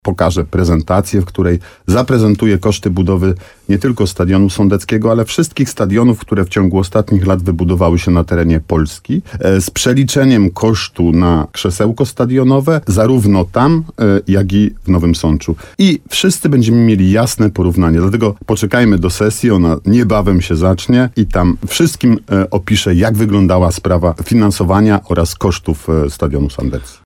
Zastępca prezydenta miasta Artur Bochenek, zapowiedział w programie Słowo za słowo w RDN Nowy Sącz, że na wtorkowej (9.09) sesji Rady Miasta Nowego Sącza poda informacje na ten temat.